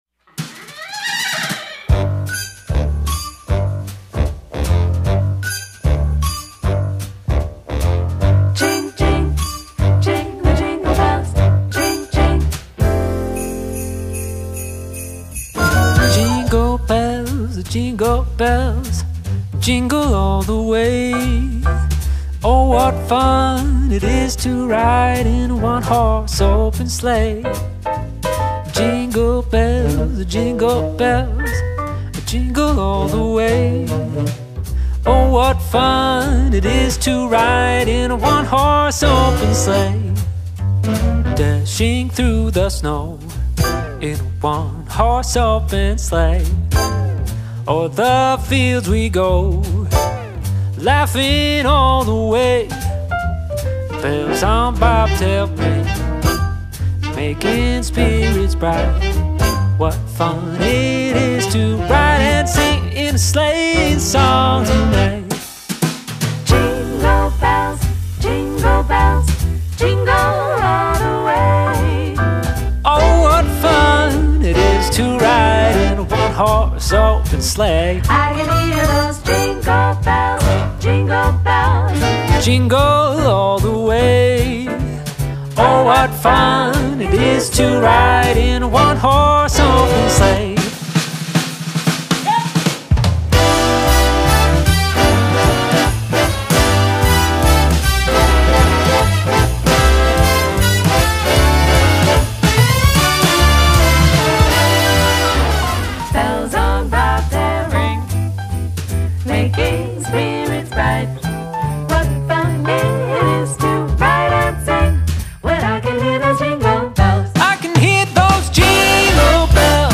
Jazz
upright